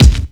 • Rich High End Kick Drum Sample C Key 674.wav
Royality free kick drum single shot tuned to the C note. Loudest frequency: 701Hz
rich-high-end-kick-drum-sample-c-key-674-4A9.wav